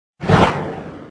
Whistle.mp3